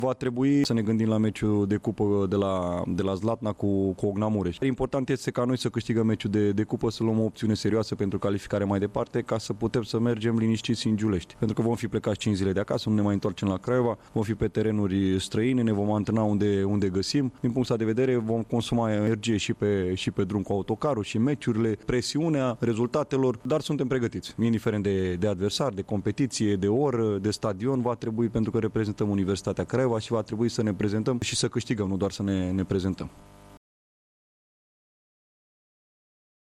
La finalul jocului de acasă cu ”U” Cluj din Superliga de fotbal, câștigat cu 1-0, antrenorul principal al formației CS Universitatea Craiova, Mirel Rădoi, a vorbit și despre confruntarea din Cupa României de la Zlatna, subliniind că echipa sa vizează o victorie care să-i asigure calificarea în faza următoare.